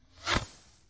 烟花 " 瓶装火箭07失火
描述：使用Tascam DR05板载麦克风和Tascam DR60的组合使用立体声领夹式麦克风和Sennheiser MD421录制烟花。
Tag: 高手 焰火 裂纹